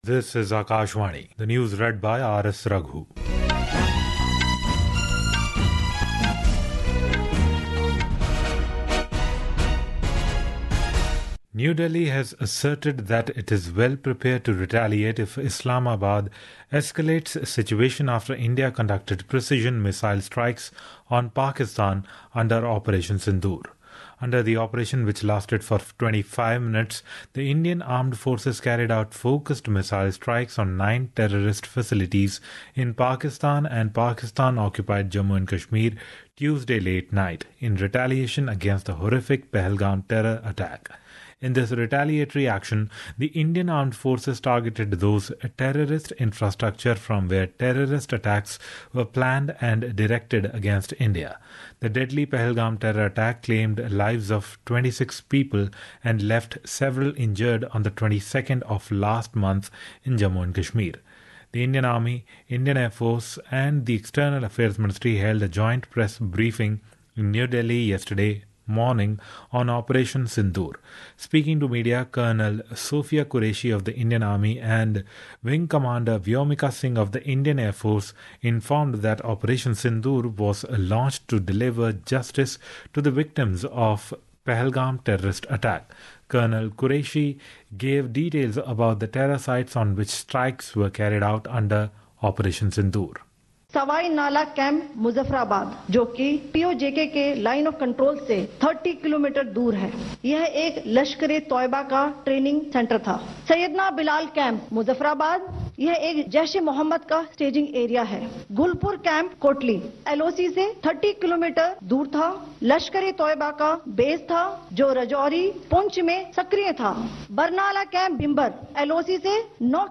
Hourly News | English